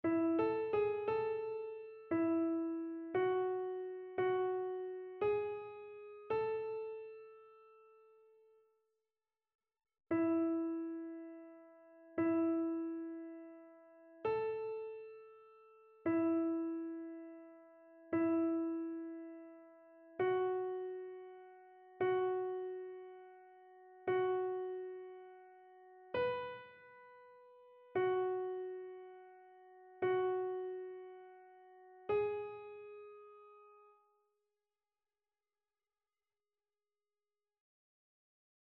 Soprano
annee-a-temps-ordinaire-26e-dimanche-psaume-24-soprano.mp3